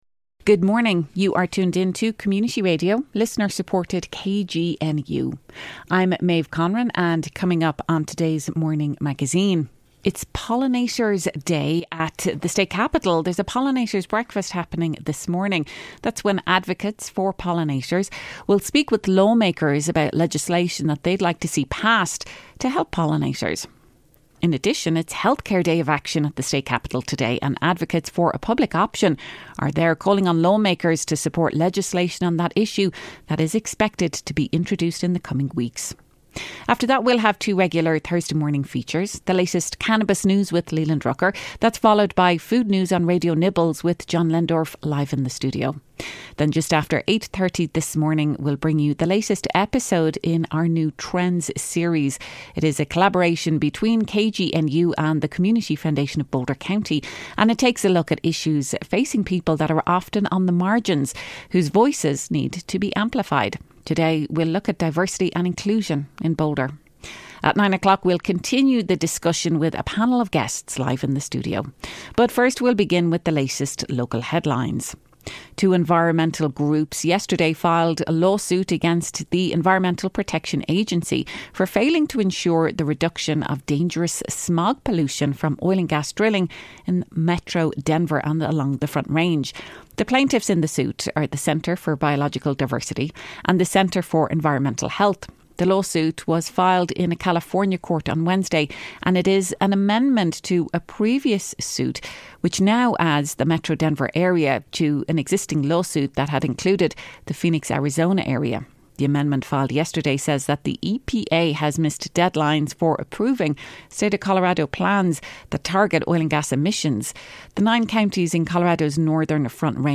It’s Pollinator Day and a Health Care Day of Action at the state capitol. We’ll hear reports on legislative actions underway related to both those topics before our regular Thursday morning updates on cannabis news and food news.